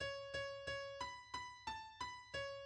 key Bm